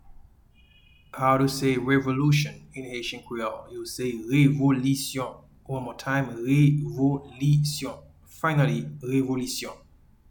Pronunciation:
Revolution-in-Haitian-Creole-Revolisyon.mp3